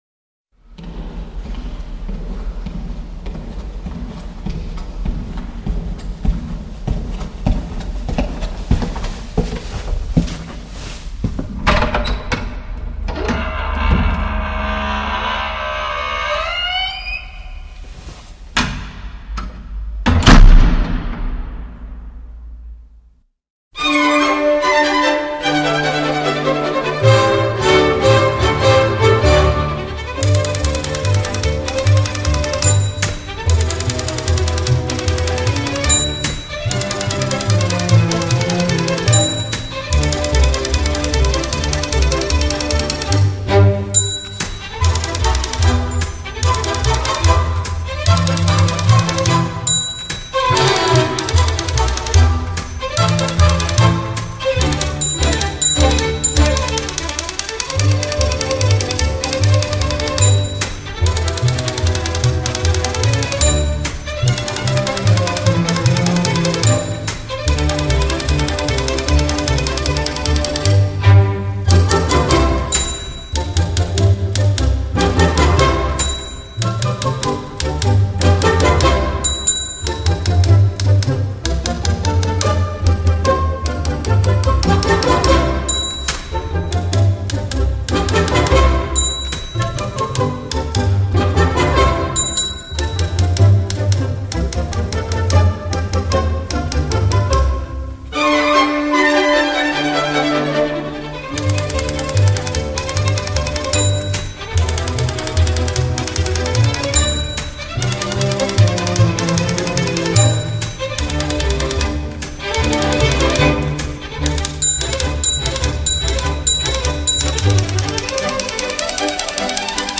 专辑格式：DTS-CD-5.1声道
整体听感声音通透、背景宁静、选曲易听、